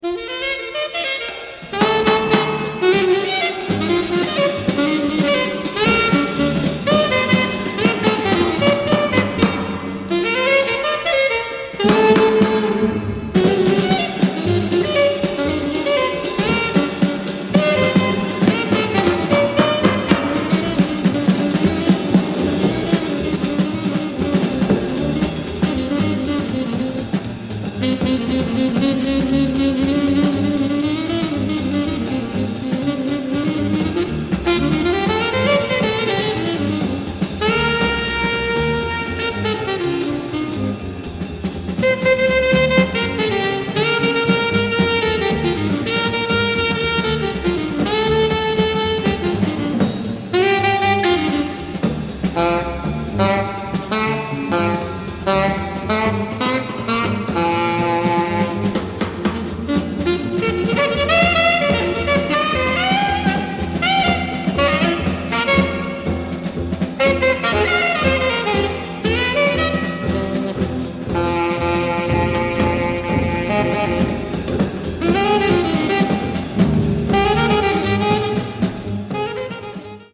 Recorded live in Paris 197.